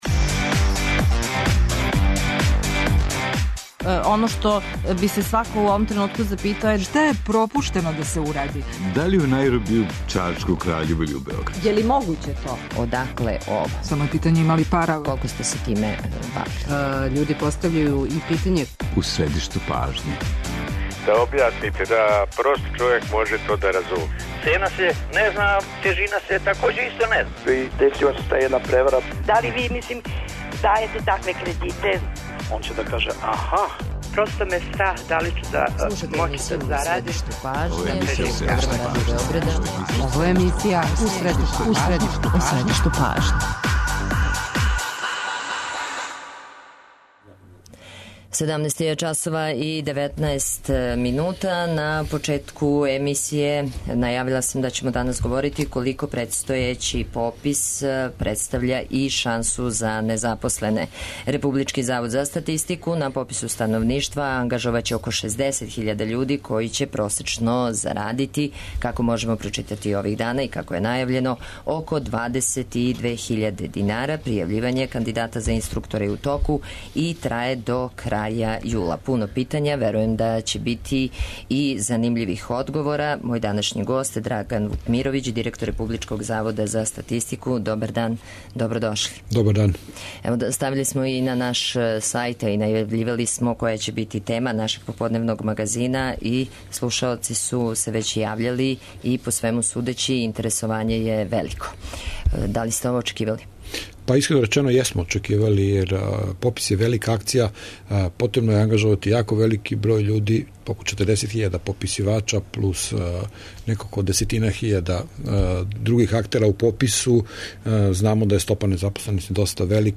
Први пут се приликом пописа користи компјутерски систем за очитавање података, па ће приоритет имати кандидати са читким и правилним рукописом. Гост Драган Вукмировић, директор Републичког завода за статистику.
преузми : 18.88 MB У средишту пажње Autor: Редакција магазинског програма Свакога радног дана од 17 часова емисија "У средишту пажње" доноси интервју са нашим најбољим аналитичарима и коментаторима, политичарима и експертима, друштвеним иноваторима и другим познатим личностима, или личностима које ће убрзо постати познате.